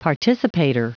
Prononciation du mot participator en anglais (fichier audio)
Prononciation du mot : participator